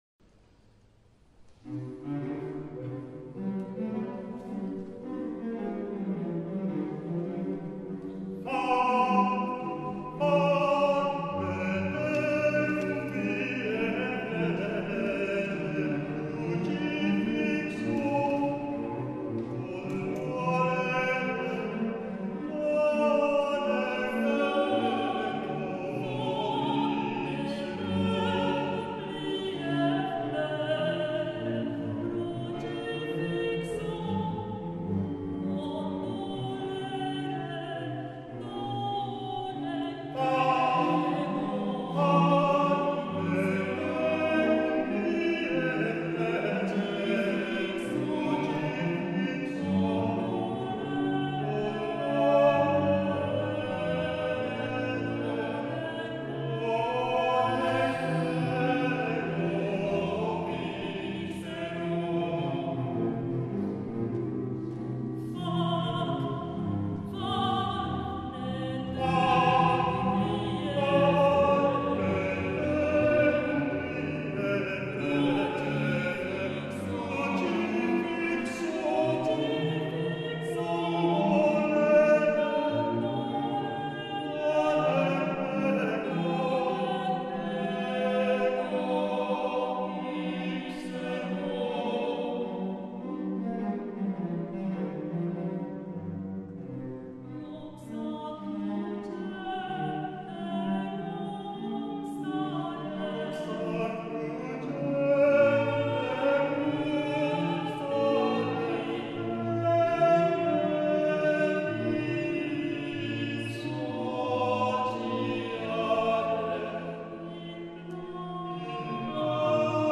Soloist Music